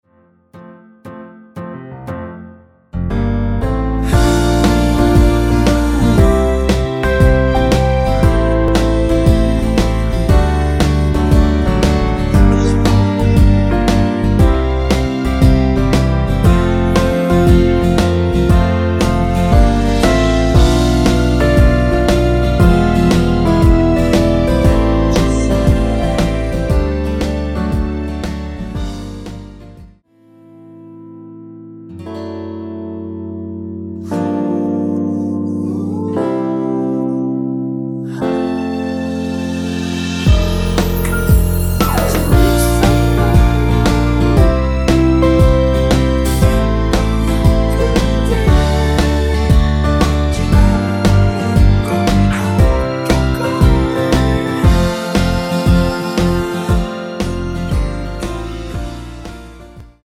전주 없이 시작하는 곡이라 전주 만들어 놓았습니다.(일반 MR 미리듣기 확인)
원키에서(-1)내린 코러스 포함된 MR입니다.
앞부분30초, 뒷부분30초씩 편집해서 올려 드리고 있습니다.
중간에 음이 끈어지고 다시 나오는 이유는